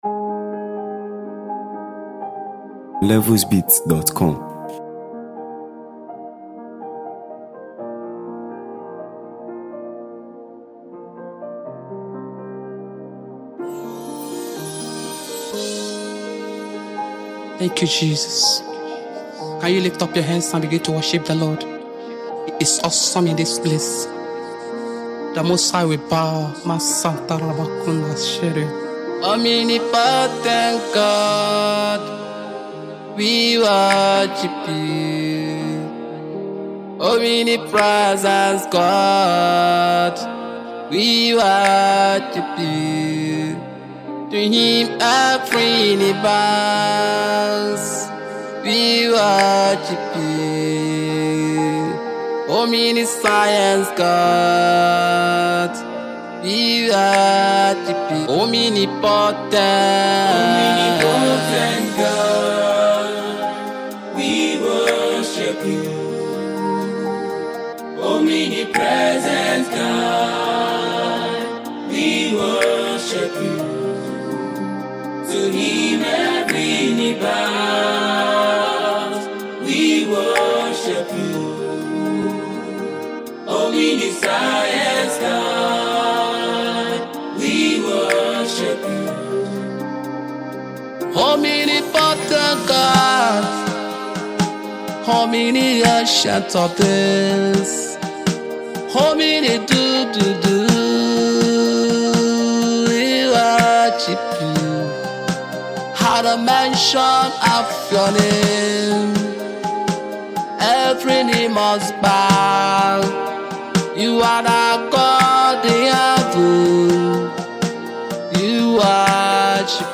Gospel
captivating and soulful track